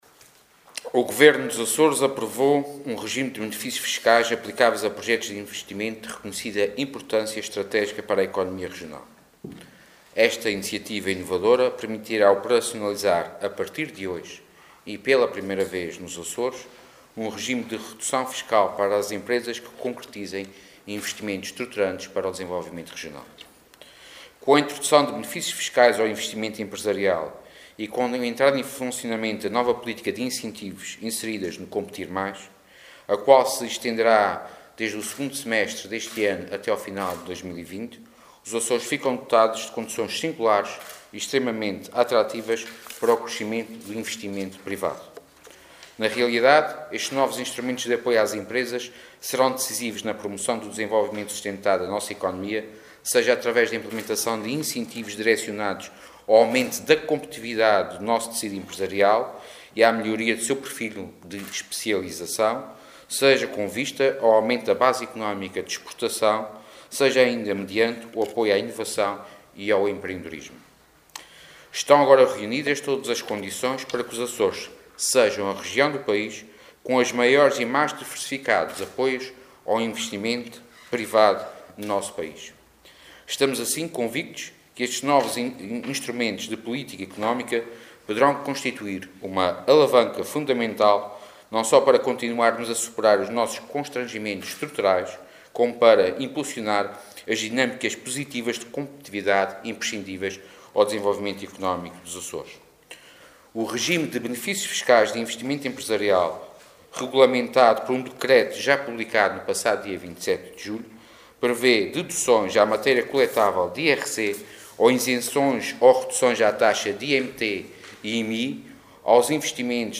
Intervenção do Vice-Presidente do Governo dos Açores